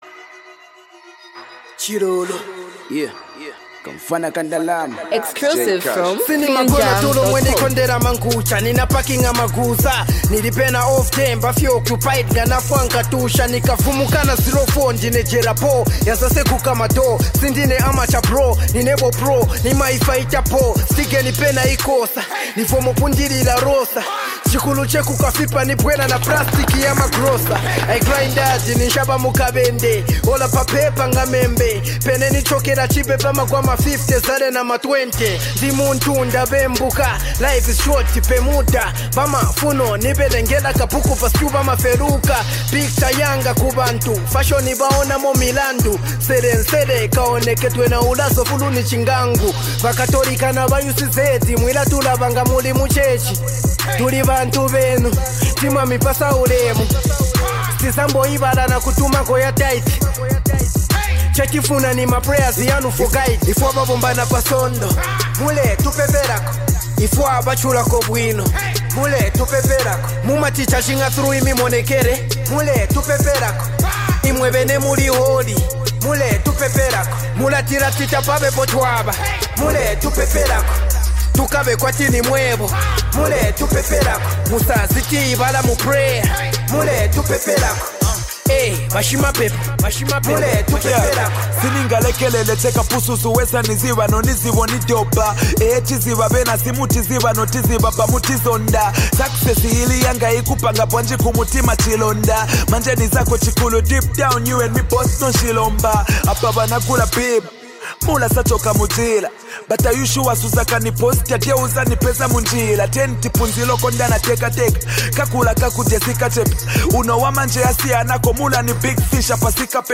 heartfelt and reflective hip-hop track
signature street-inspired flow and confident tone
rap song